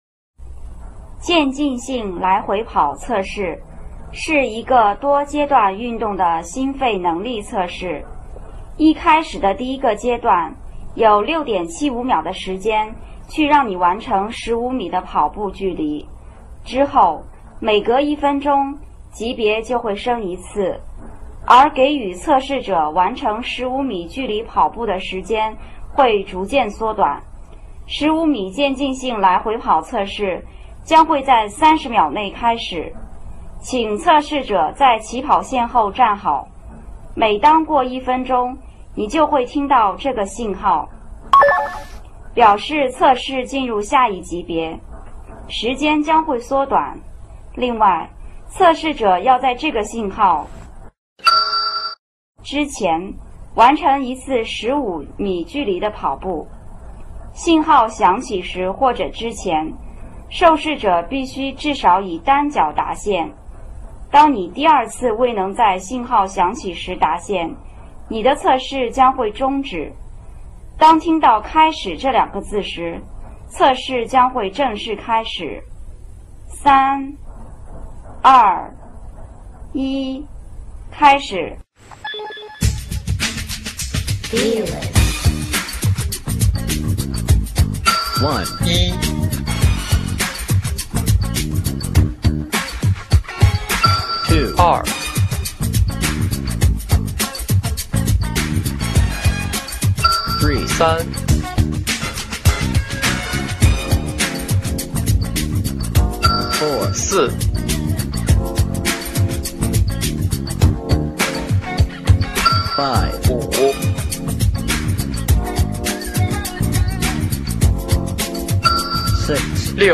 体育现场测试15米折返跑伴奏音乐.mp3
往返指令由音乐节奏控制，每次“叮”指令响起之前测试生至少要以单脚达线，听到“叮”的指令后测试生才开始下一次的折返跑，循环往复。